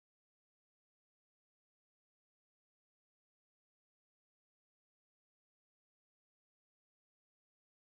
When using the nitro in the game (B button with the GC controller), you can hear the audio crackle.
The crackling in the dump is less bad than when actually playing.